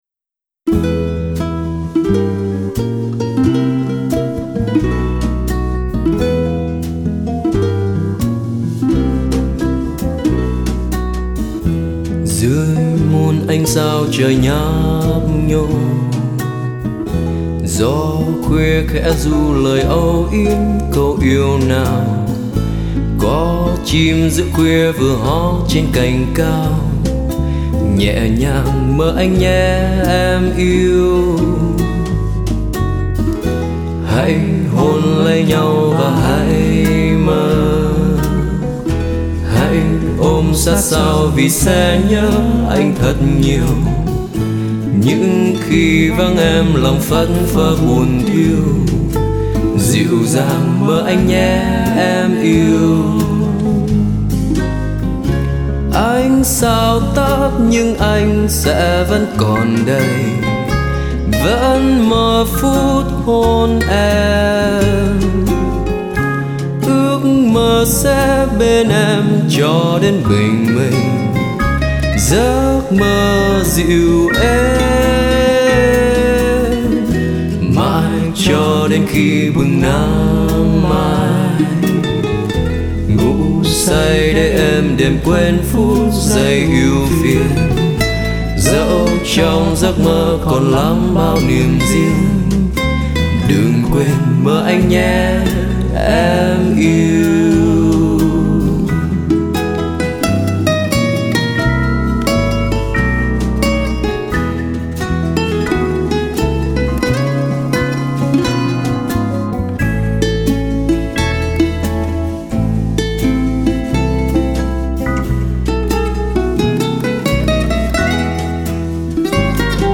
một nhạc phẩm jazz standard